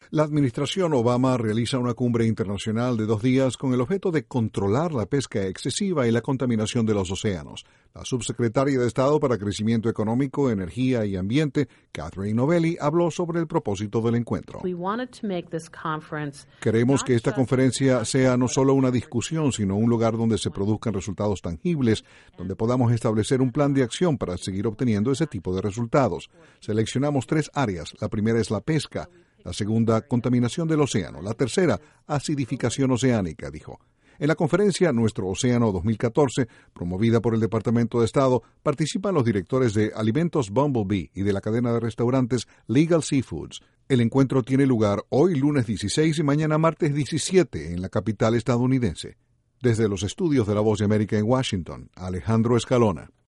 INTRO EEUU busca resultados tangibles en una conferencia contra la contaminación del mar. Desde la Voz de América en Washington